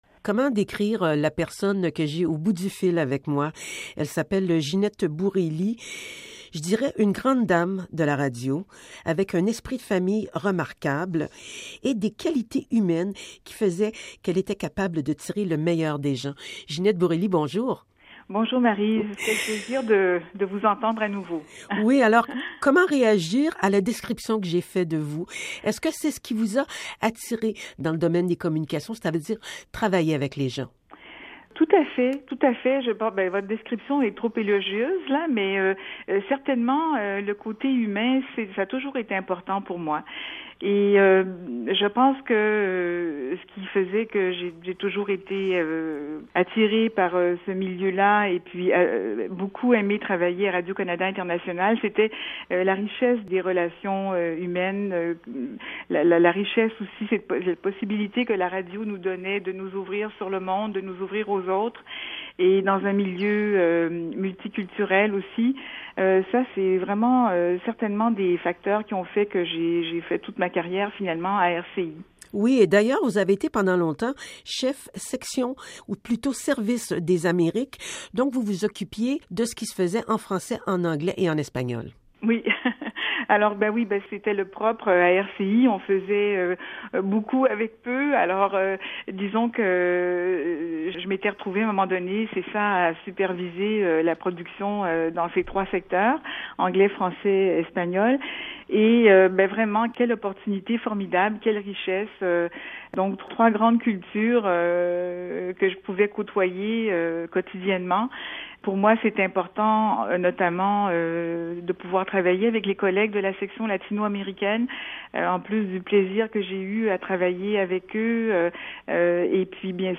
s’est entretenue avec